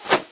/hl2/sound/npc/antlion_guard/far/
hop1.ogg